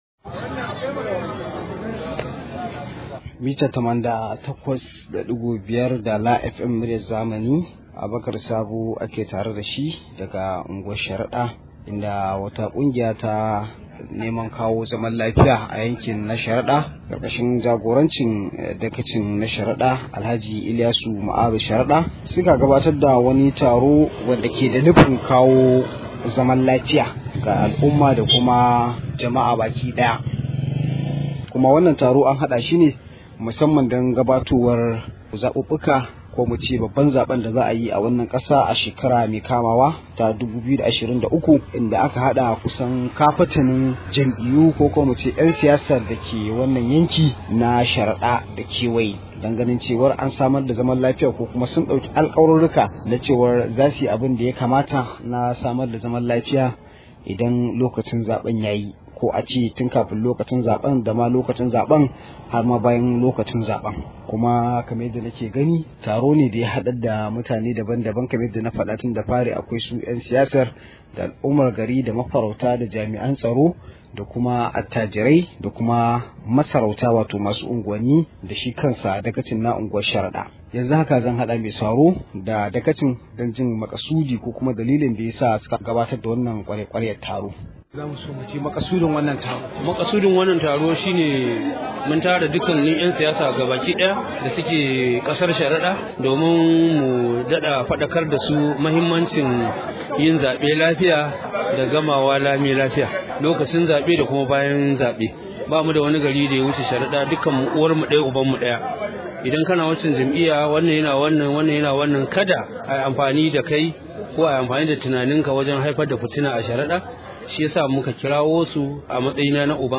Rahoto: ‘Yan siyasar Sharada ku zama tsintsiya madaurin ki daya a lokacin zabe – Dagacin Sharada
Akwai cikakken rahoton a muryar da ke kasa.